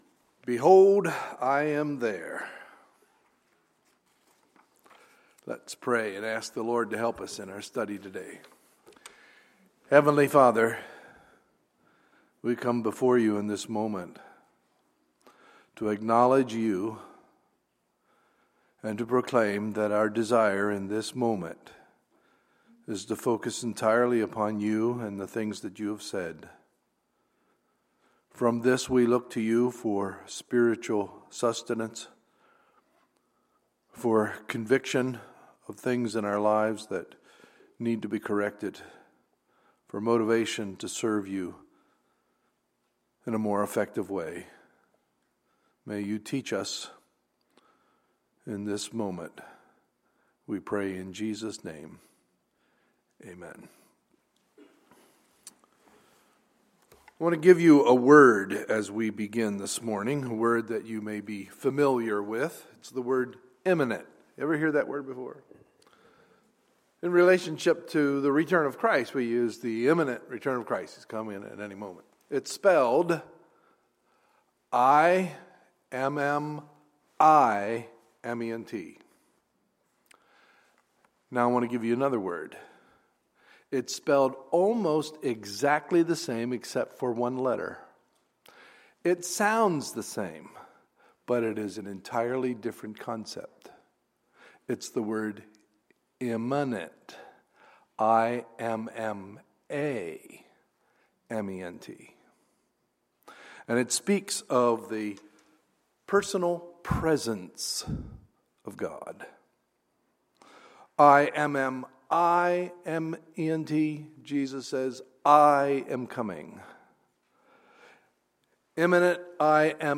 Sunday, January 17, 2016 – Sunday Morning Service